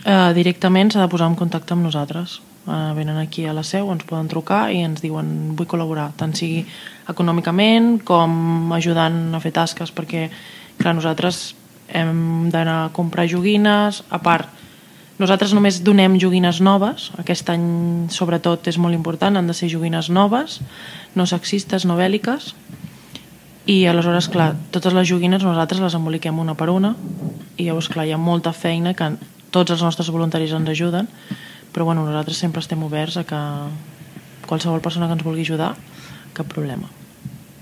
SupermatíEntrevistes Supermatí